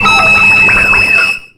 Grito de Beheeyem.ogg
Grito_de_Beheeyem.ogg